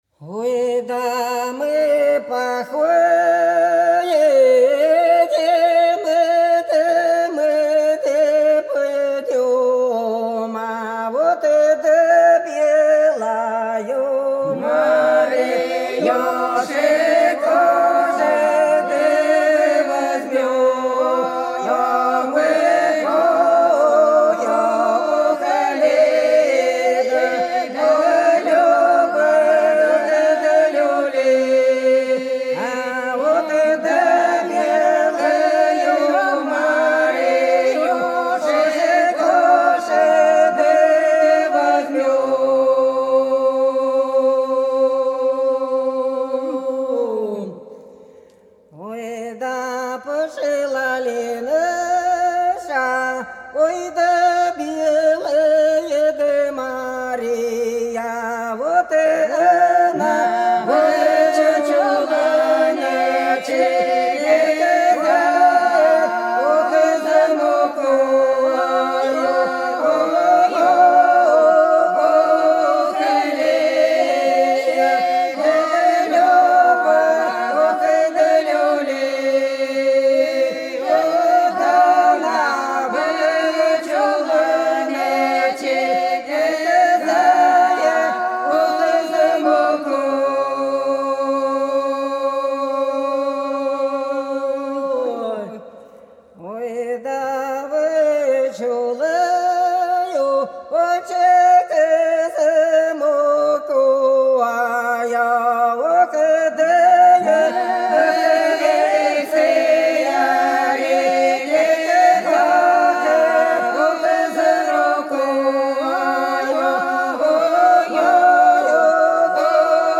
Белгородские поля (Поют народные исполнители села Прудки Красногвардейского района Белгородской области) Ой, да мы походим, мы пойдем - протяжная постовая